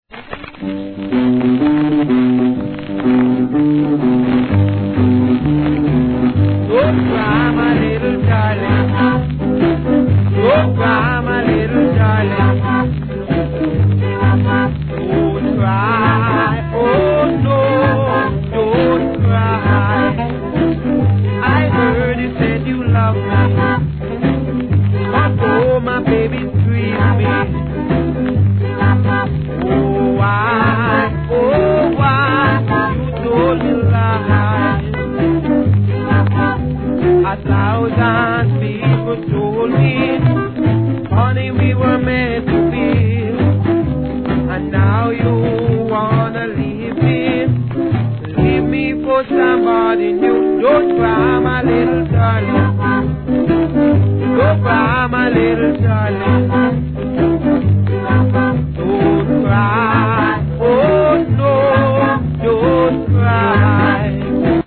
C (頭ヒスありますが、すぐに良くなります)
1. REGGAE